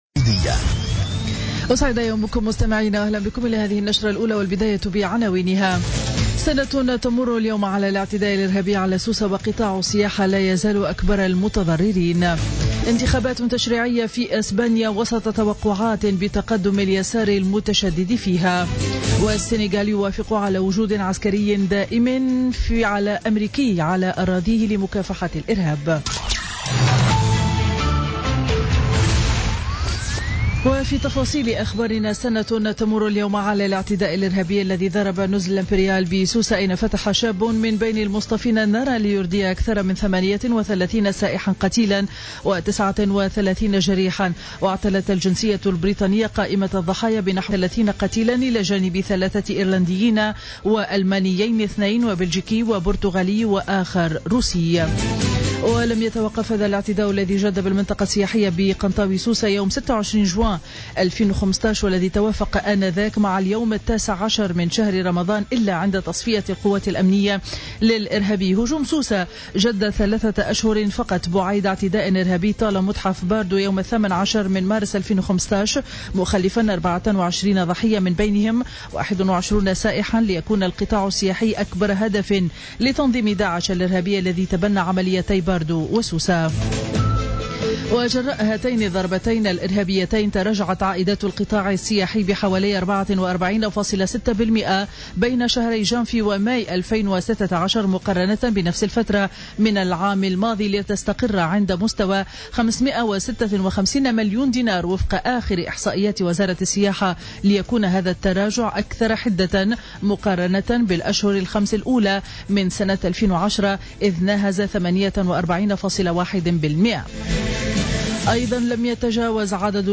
نشرة أخبار السابعة صباحا ليوم الأحد 26 جوان 2016